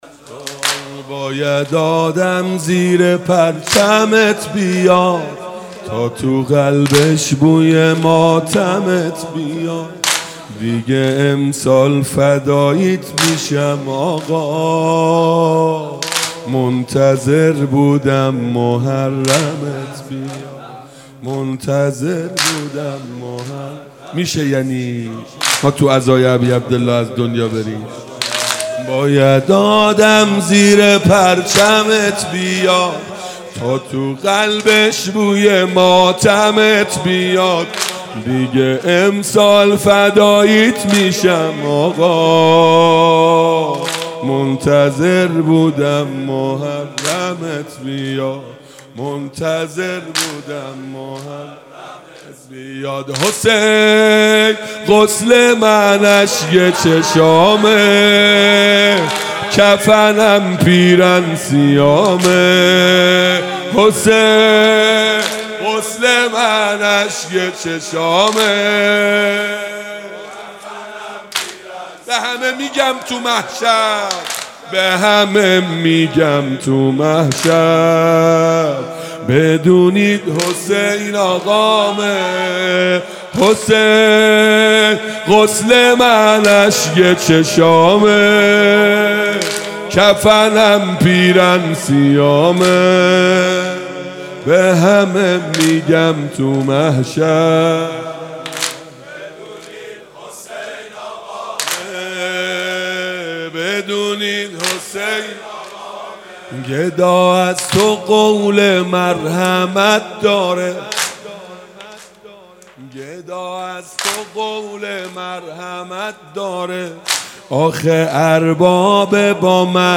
شب پنجم محرم 97 - واحد - باید آدم زیر پرچمت بیاد